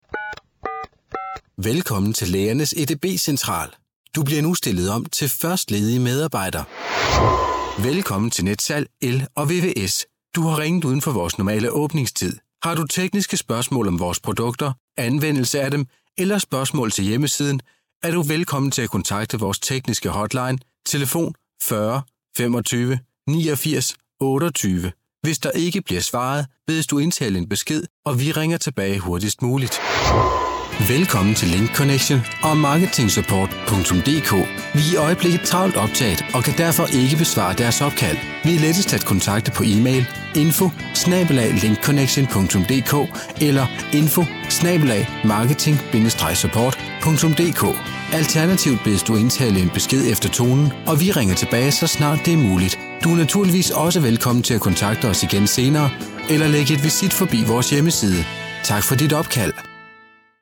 Danish voice over from a leading voice over artist - Native from Denmark
Sprechprobe: Industrie (Muttersprache):